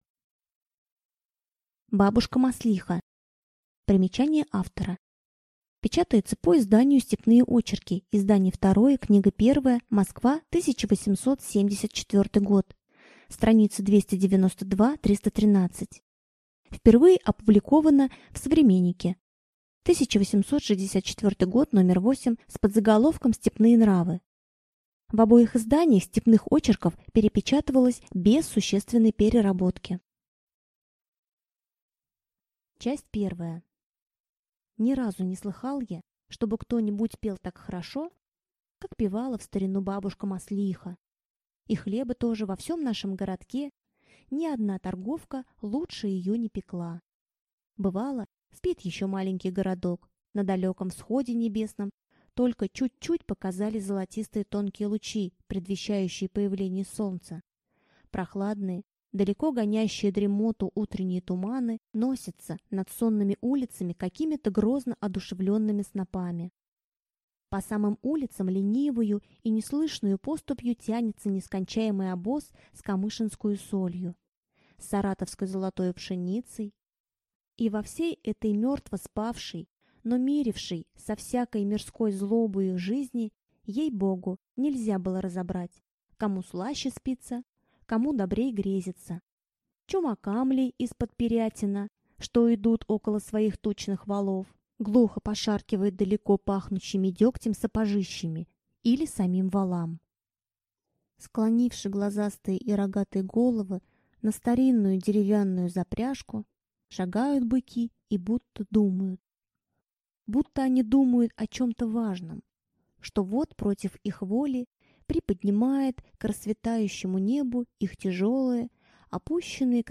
Аудиокнига Бабушка Маслиха | Библиотека аудиокниг
Прослушать и бесплатно скачать фрагмент аудиокниги